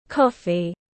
Cà phê tiếng anh gọi là coffee, phiên âm tiếng anh đọc là /ˈkɒf.i/